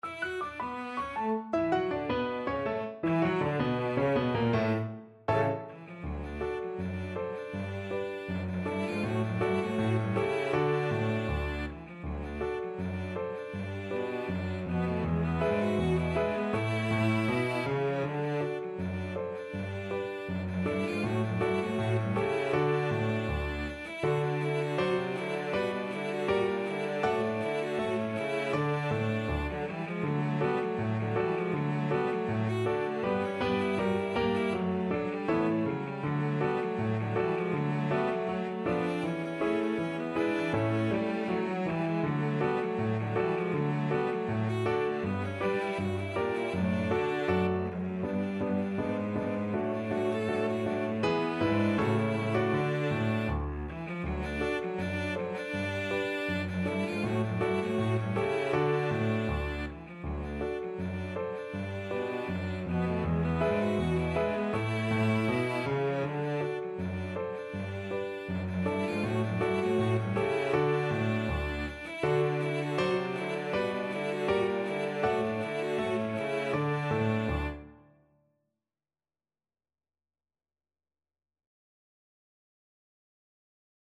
Free Sheet music for Cello Duet
Cello 1Cello 2Piano
"The Entertainer" is sub-titled "A rag time two step", which was a form of dance popular until about 1911, and a style which was common among rags written at the time.
D major (Sounding Pitch) (View more D major Music for Cello Duet )
4/4 (View more 4/4 Music)
Presto (View more music marked Presto)
Jazz (View more Jazz Cello Duet Music)